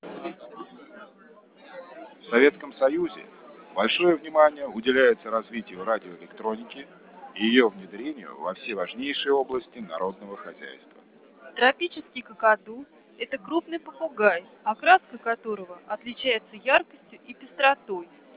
Возникающая ошибка аппроксимации спектра речи может проявлять себя на выходе в виде так называемого "музыкального шума", который в данном алгоритме значительно снижен путем выбора подходящей функции адаптивной фильтрации при обработке спектра, а также за счет сглаживания в частотной и во временнОй областях.
Шум толпы, ОСШ +12 дБ
Речь на выходе ШПУ
fspeech_babble_+12dB_fxp_enh.wav